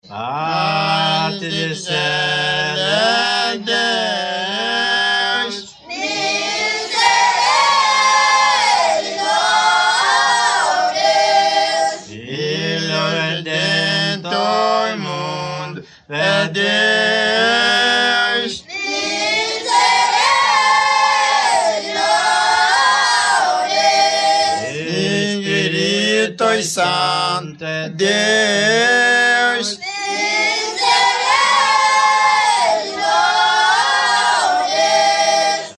Canto cerimonial executado como responsório, baseado em textos da liturgia católica, É prece cantada para evocar a proteção de Deus, Jesus, Virgem Maria e santos